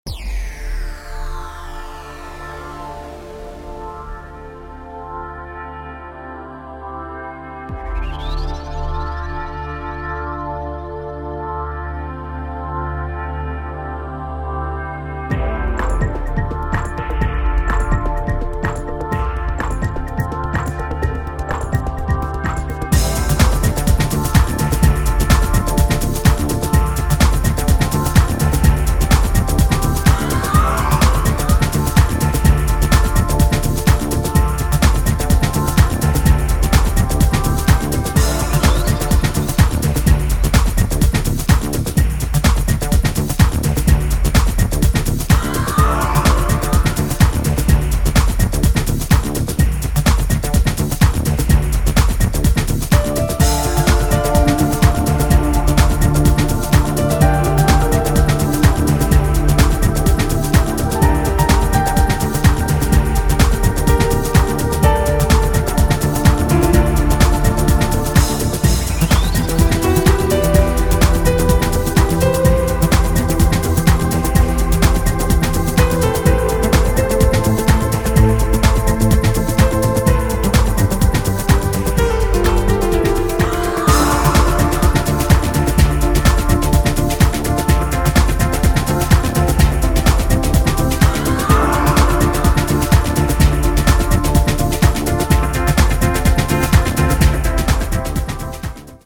trailer music